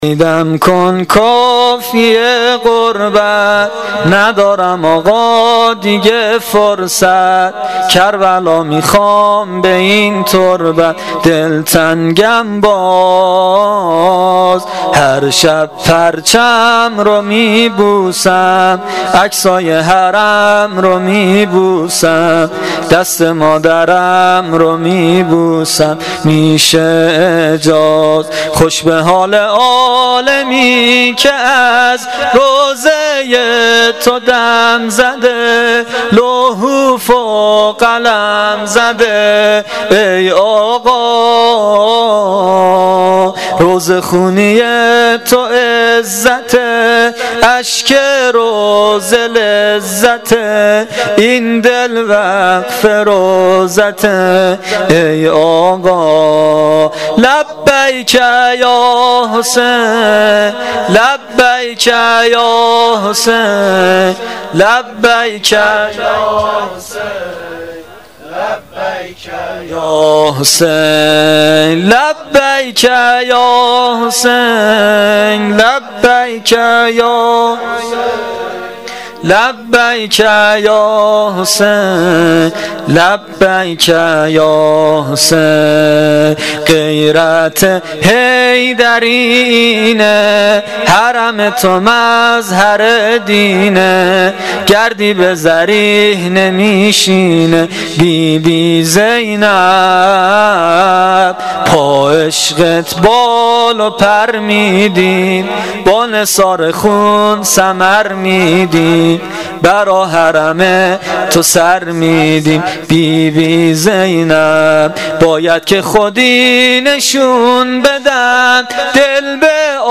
سرود پایانی شب پنجم محرم الحرام 1396